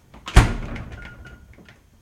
fridgeClose.wav